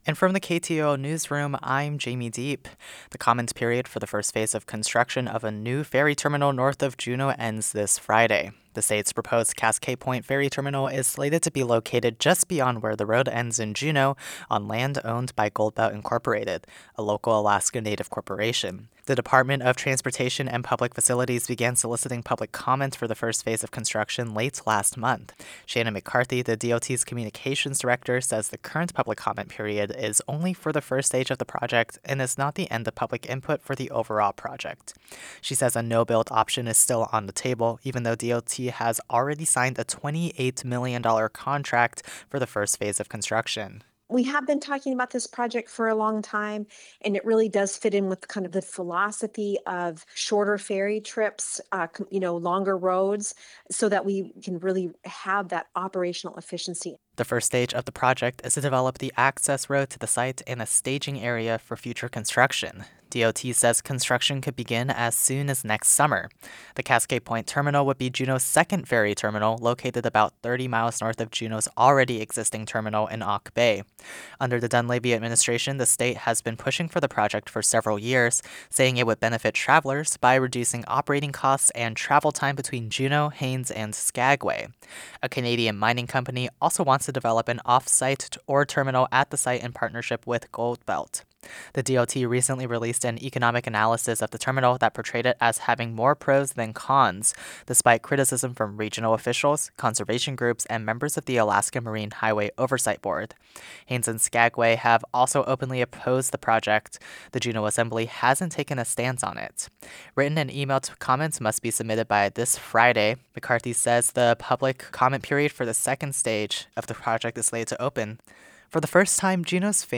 Newscast – Wednesday, Nov. 26, 2025 - Areyoupop